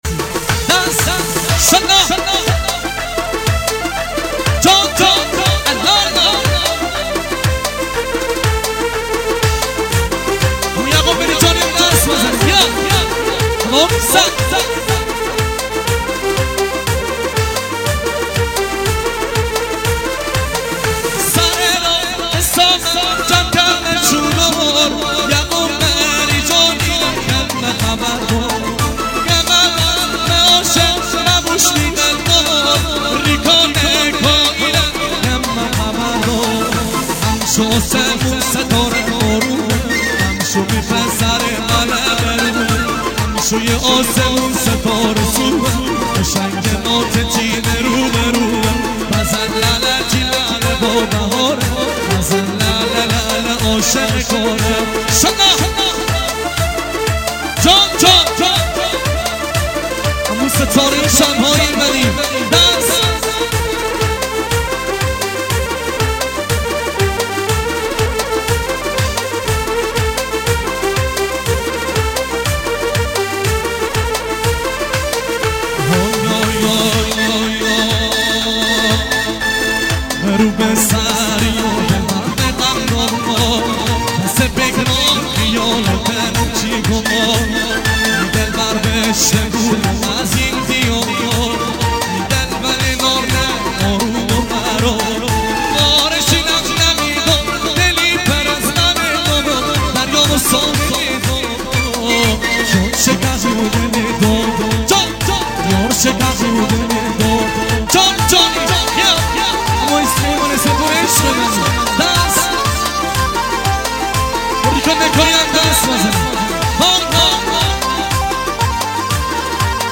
ریمیکس مازندرانی
نوازنده کیبرد
آهنگ مازندرانی